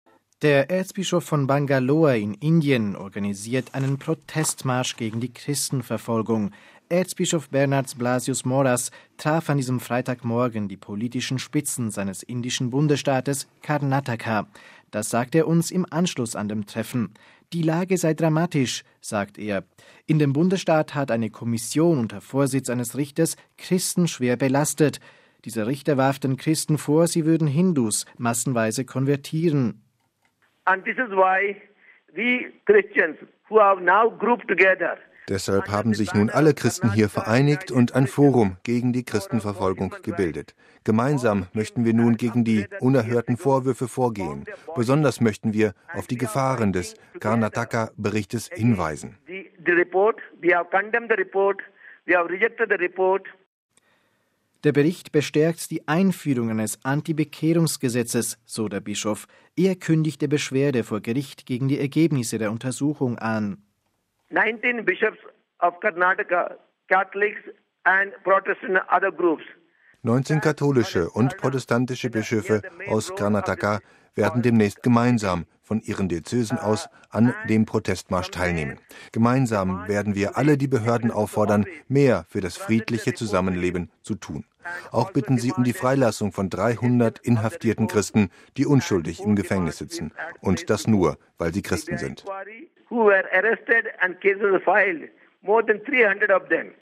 Das sagte er uns im Anschluss an dem Treffen.